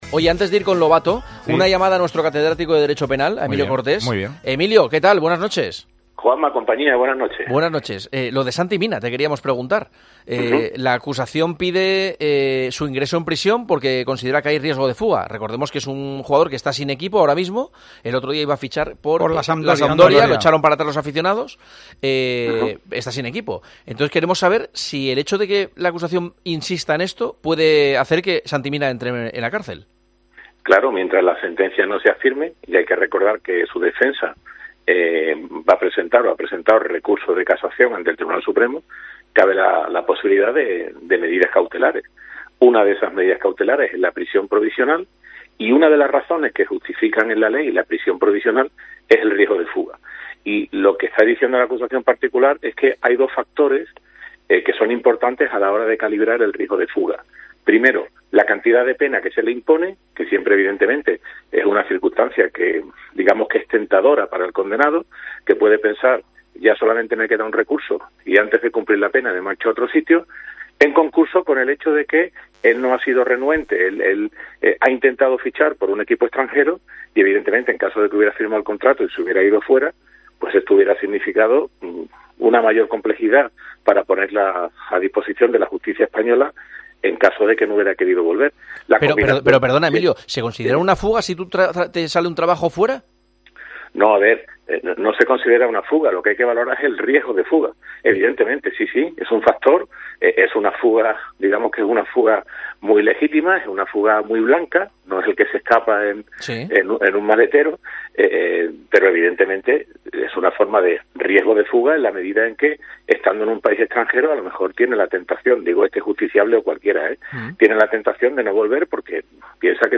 El Catedrático de Derecho Penal ha hablado con Juanma Castaño en El Partidazo y considera légitimas las acciones que ha tomado la justicia contra Santi Mina.